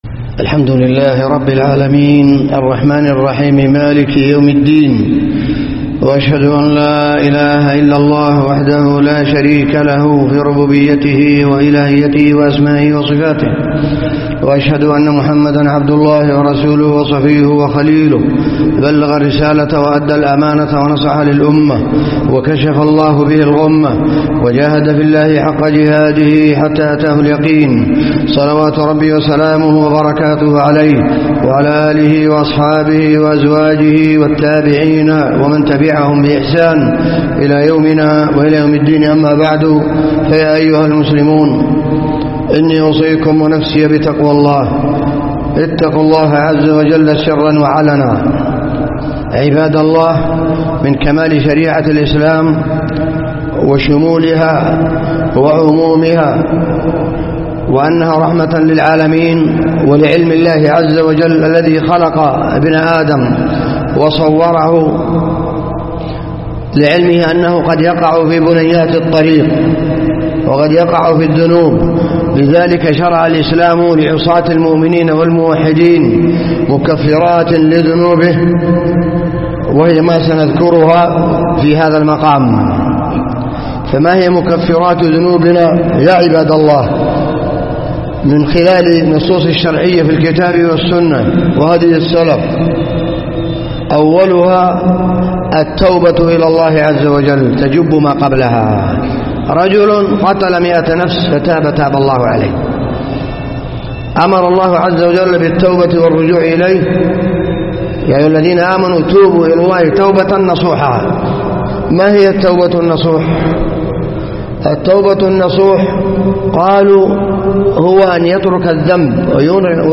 خطبة
جامع الرفاعية بمحافظة أبو عريش